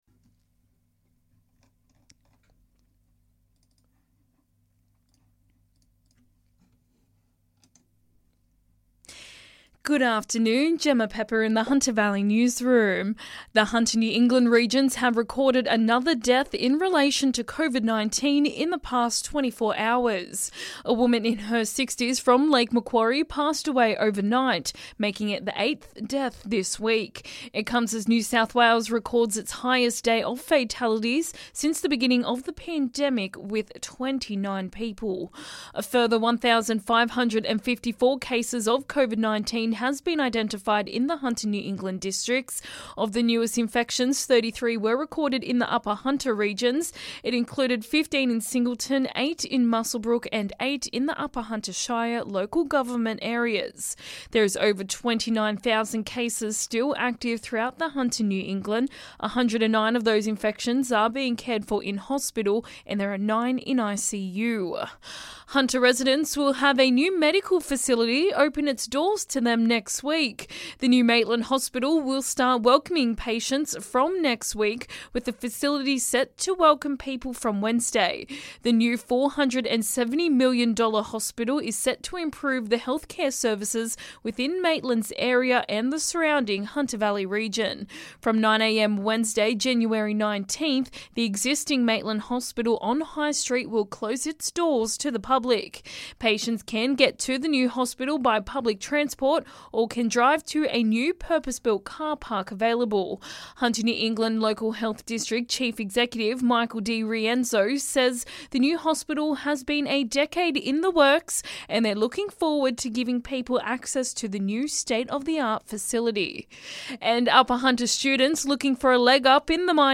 LISTEN: Hunter Valley Local News Headlines 14/01/22